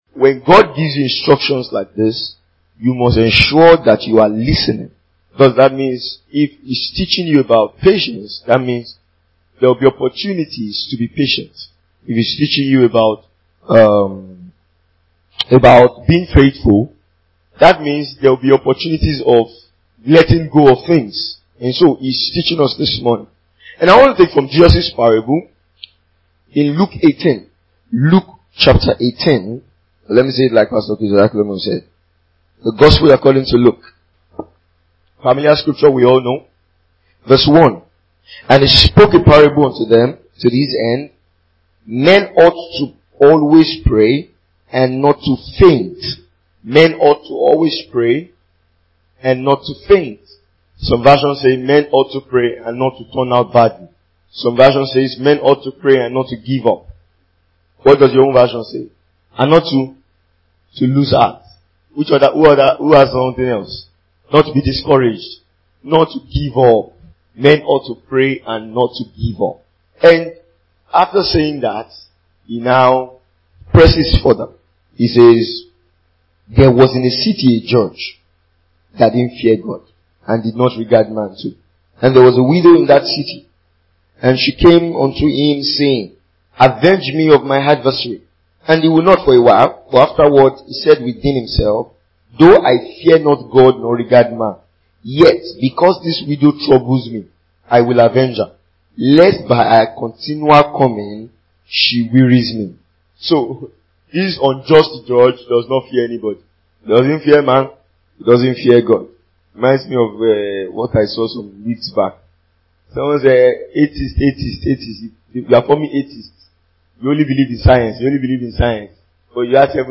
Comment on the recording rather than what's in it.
Remain faithful (Minister’s Retreat 2021)